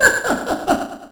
snd_joker_laugh1.ogg